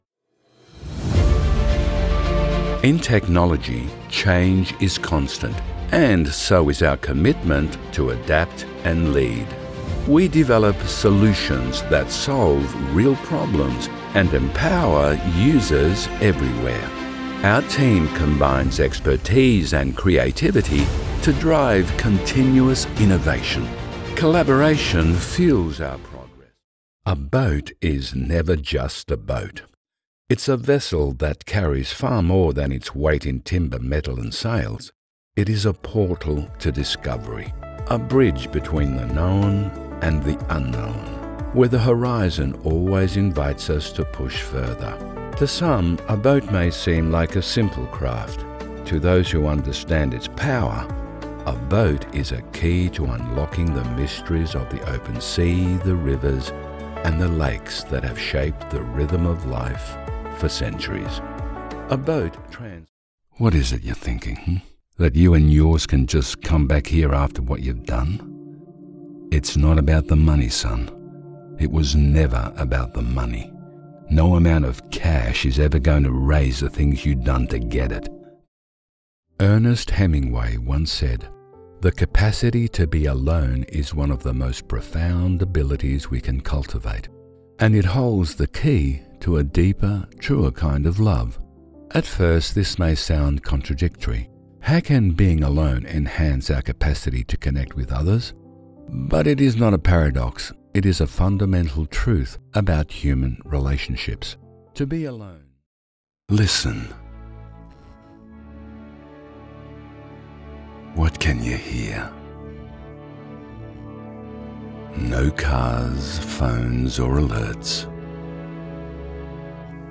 Hire Professional Male Voice Over Talent, Actors & Artists Online